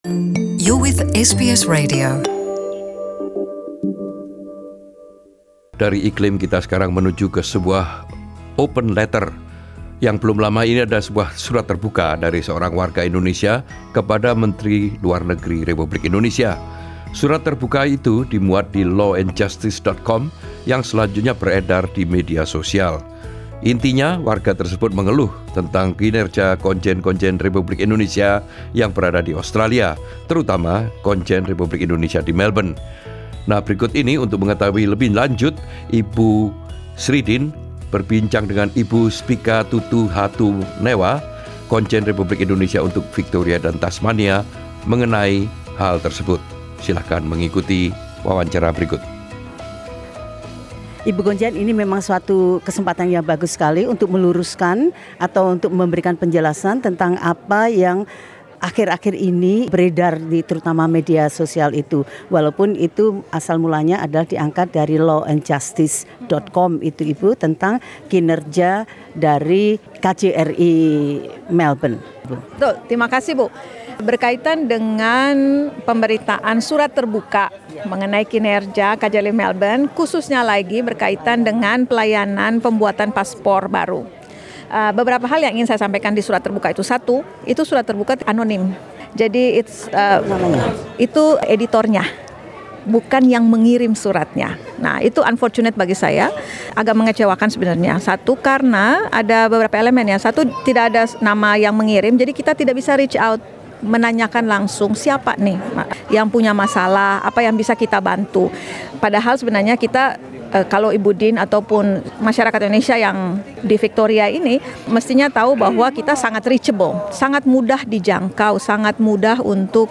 Indonesian Consul General for Victoria and Tasmania, Spica A. Tutuhatunewa speaking to SBS Indonesian at the Indonesian Pavilion at the Royal Melbourne Show, 21 Source: Courtesy: SCW.